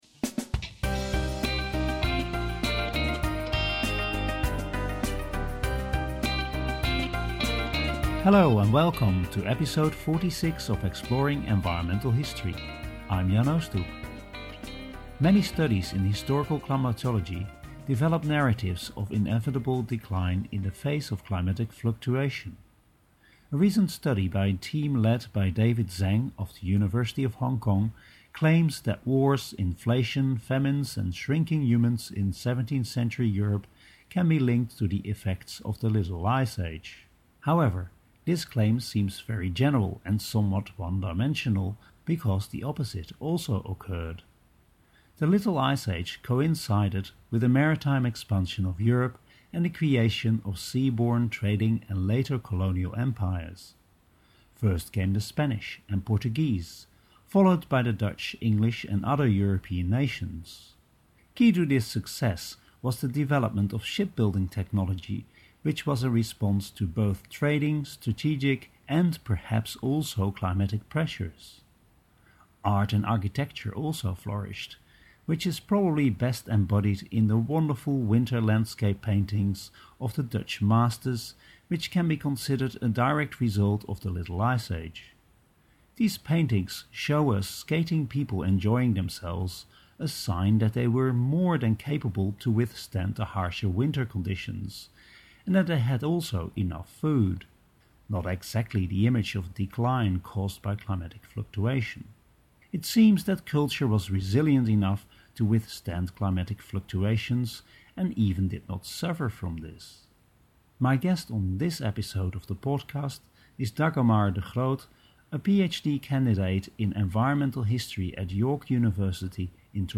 Exploring Environmental History podcasts are periodic programmes featuring interviews with people working in the field, reports on conferences and discussions about the use and methods of environmental history.